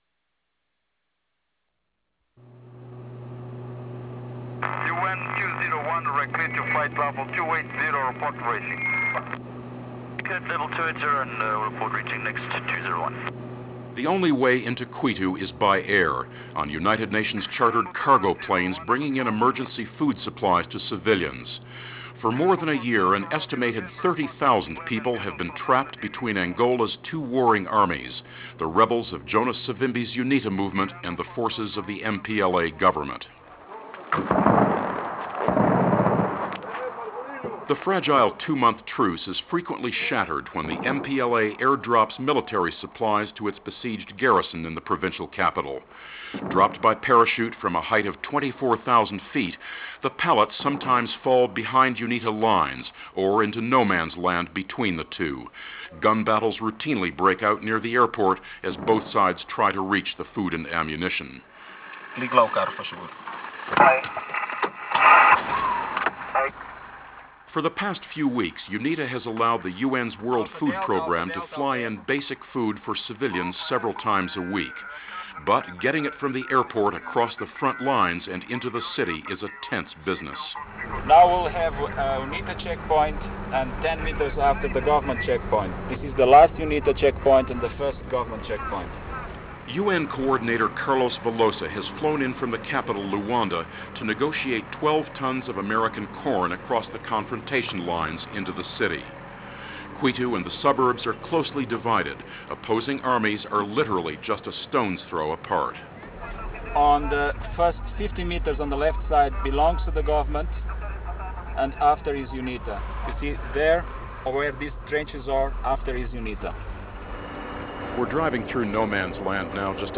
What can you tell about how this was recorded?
The report was produced by Monitor Radio and originally broadcast in February of 1994.